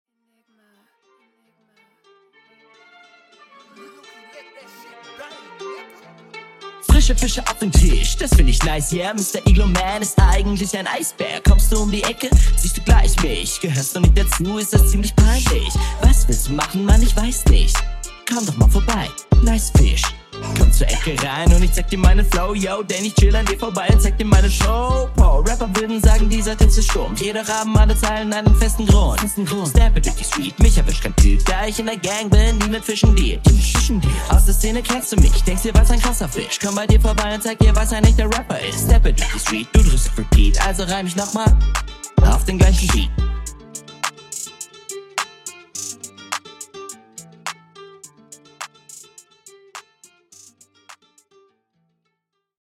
Der Takt wird an sich getroffen, aber das klingt nicht ganz stimmig - also das …